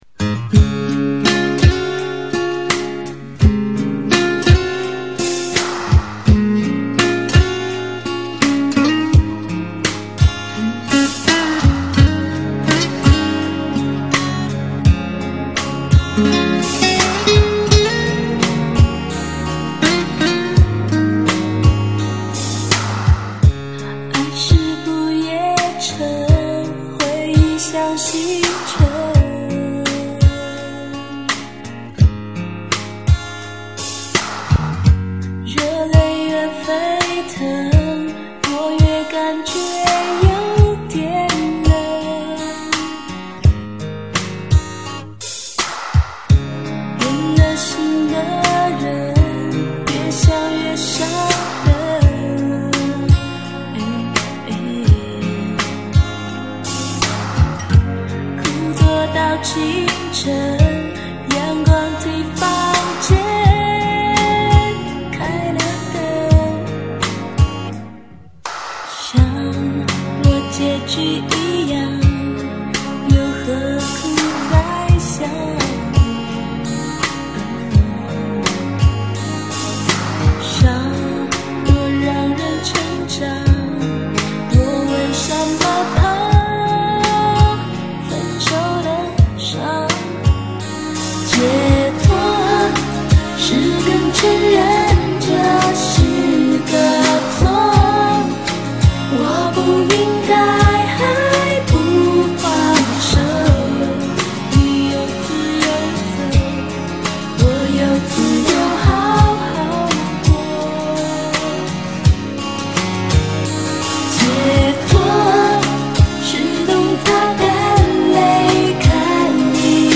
前后效果有异，不知道怎么搞的，音轨出错了